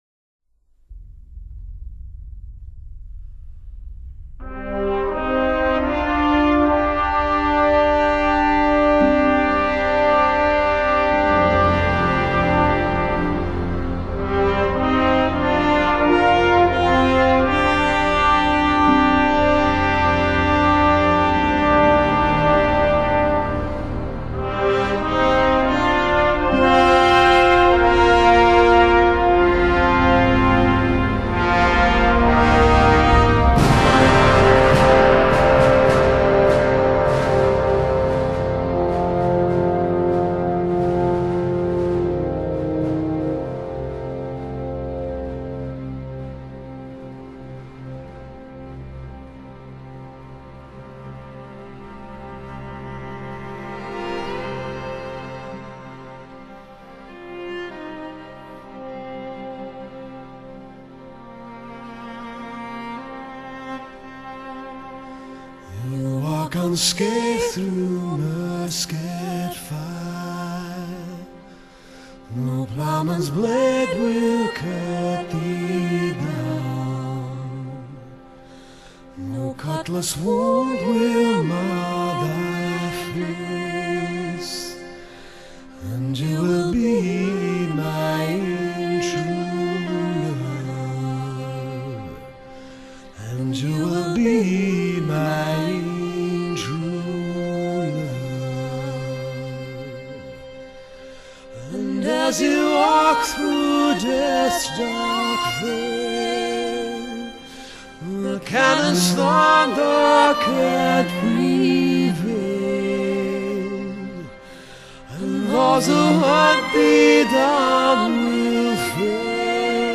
音樂風格︰Pop | 1CD |